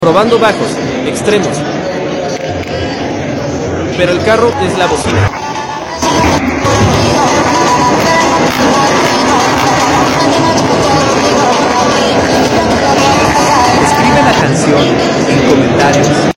🤯 El auto es la bocina.